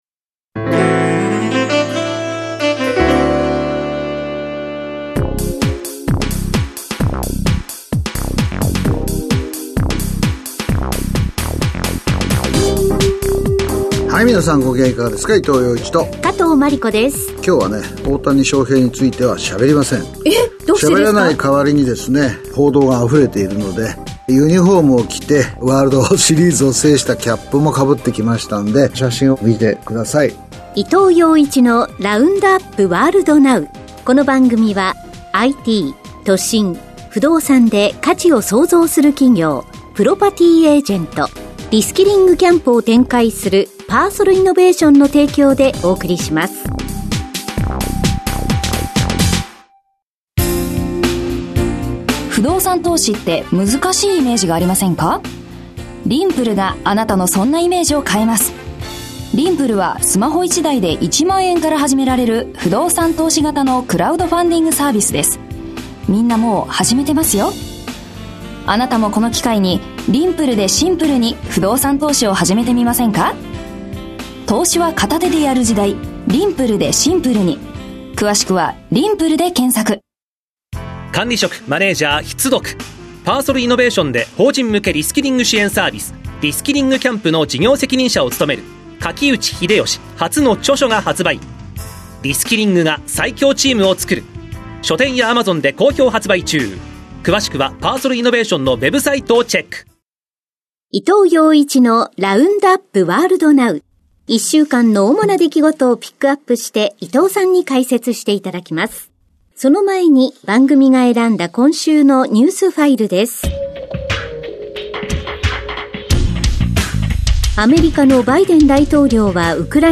… continue reading 461 tập # ニューストーク # ニュース # ビジネスニュース # NIKKEI RADIO BROADCASTING CORPORATION